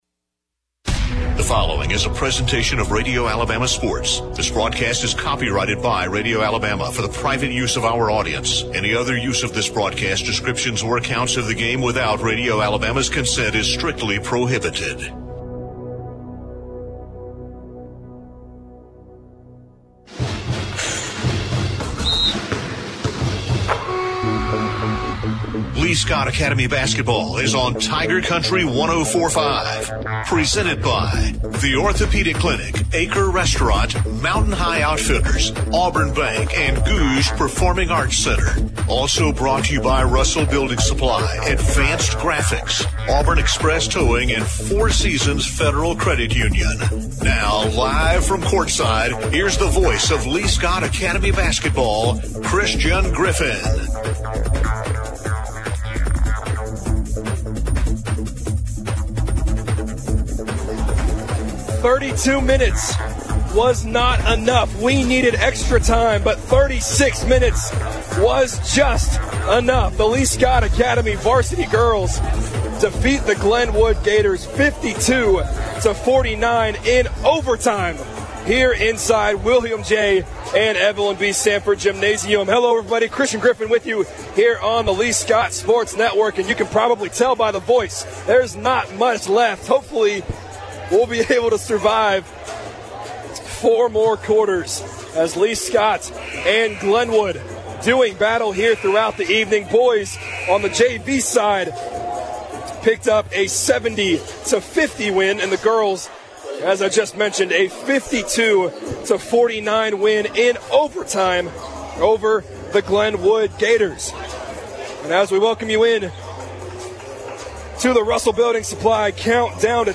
calls Lee-Scott Academy's game against the Glenwood Gators. The Warriros won 66-63.